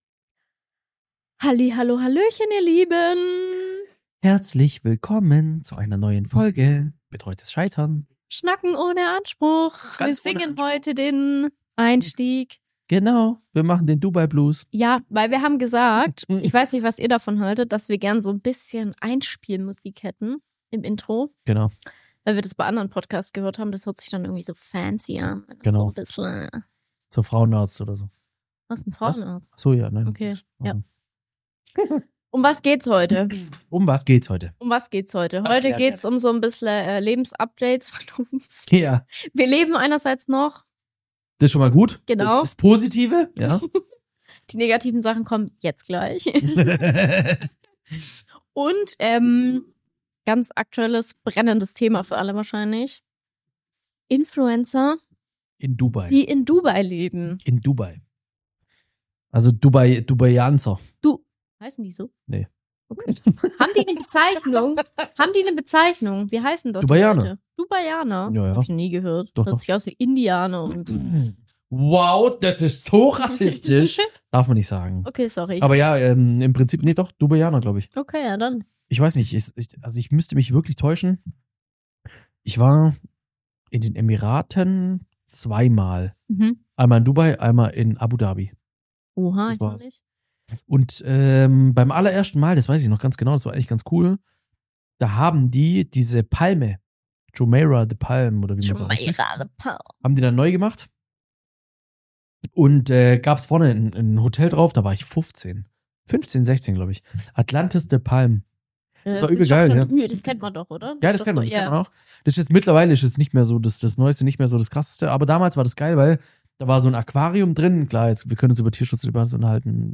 Eine ehrliche, entspannte Gesprächsrunde über Wahrnehmung, Authentizität und die Frage, wie viel Wirklichkeit hinter perfekten Feeds steckt.